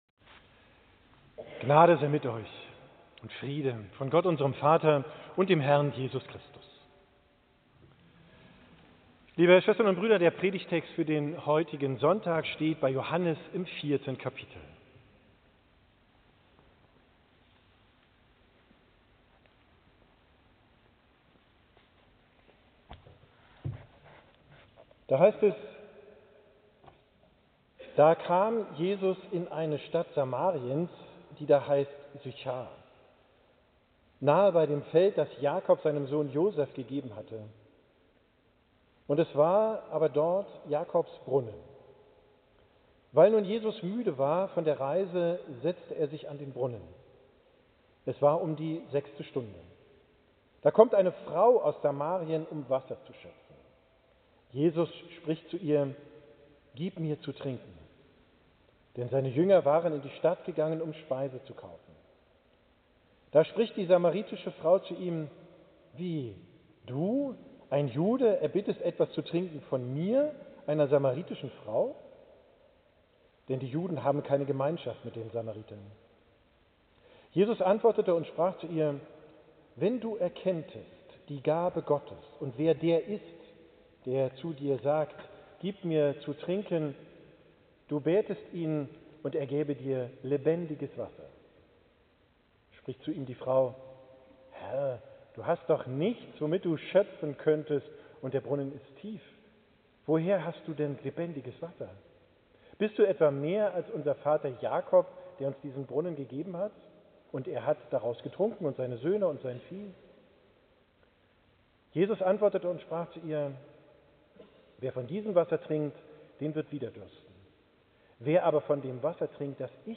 Predigt vom 3.